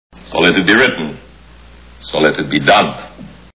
Ten Commandments Movie Sound Bites